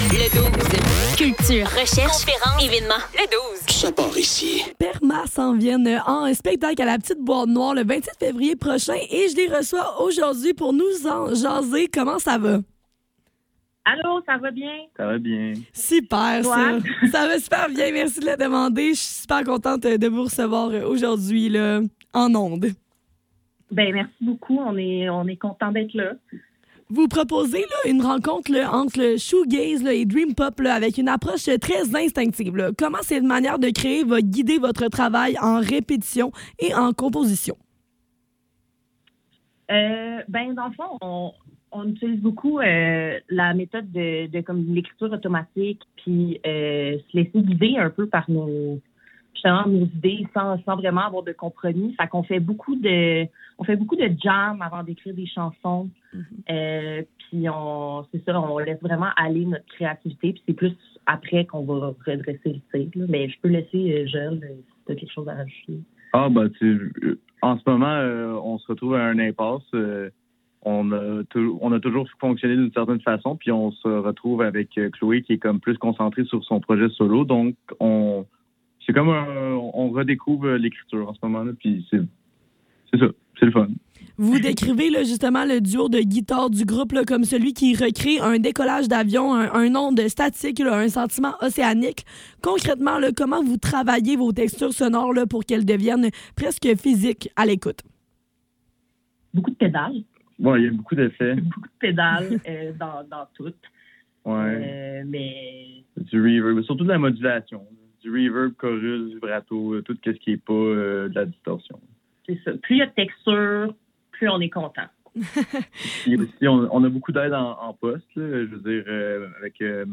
Le Douze - Entrevue